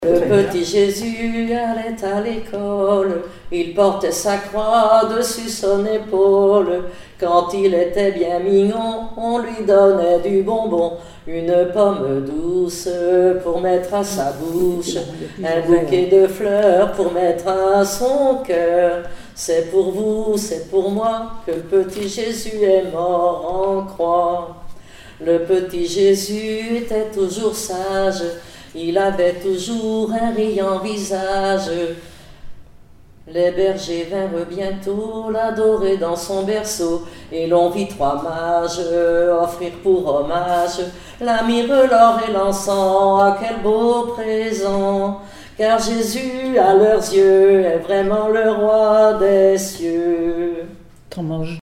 Mémoires et Patrimoines vivants - RaddO est une base de données d'archives iconographiques et sonores.
enfantine : berceuse
Pièce musicale inédite